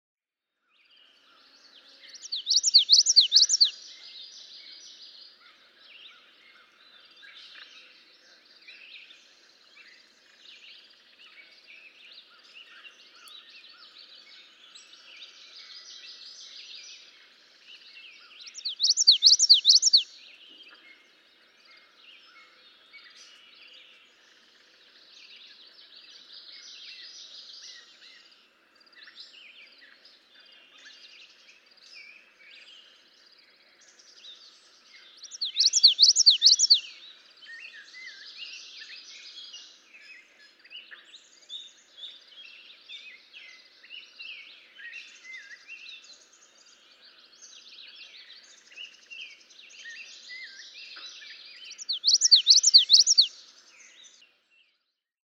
May 30, 2015. East Leverett Meadow, Leverett, Massachusetts.
♫218, ♫219—longer recordings from those two neighbors
219_Common_Yellowthroat.mp3